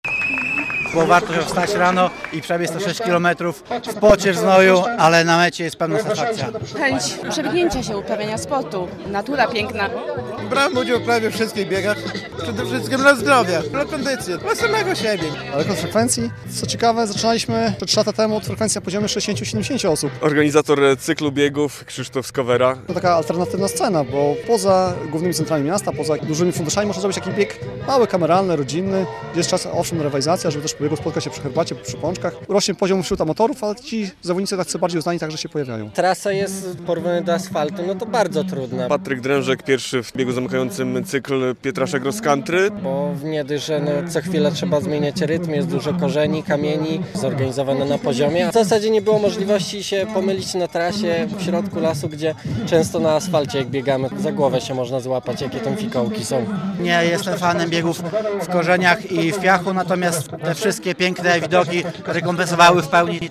Około 170 biegaczy na trasie Pietrasze Cross Country - relacja